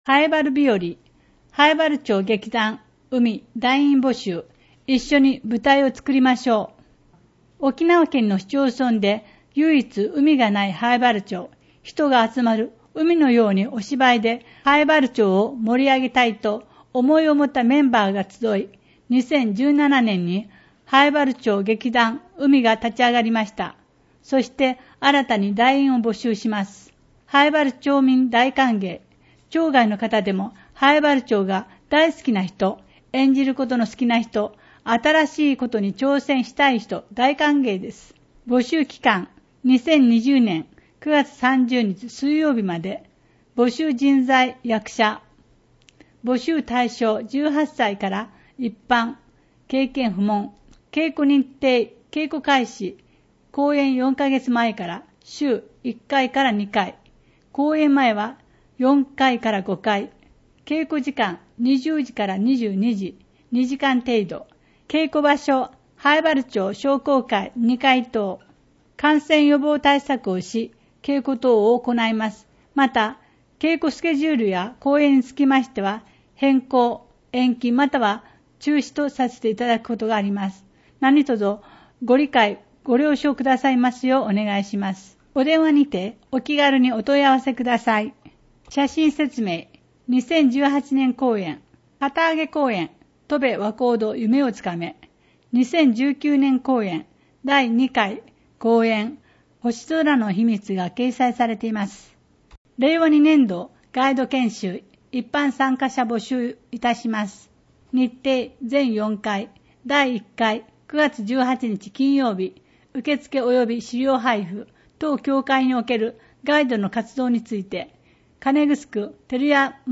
ページ 内容・見出し PDFファイル 声の広報